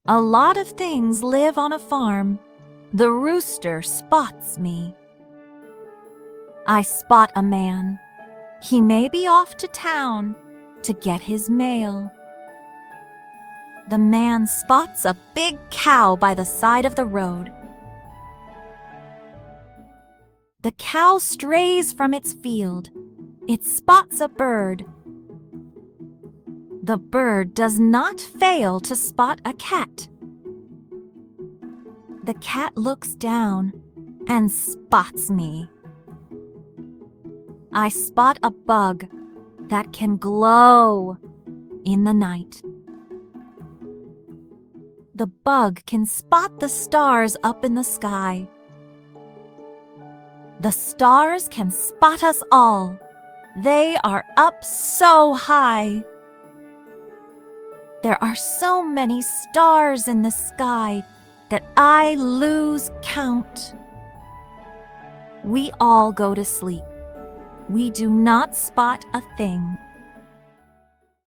Words with 'ai' or 'ay' vowel teams, both making the long /eɪ/ sound.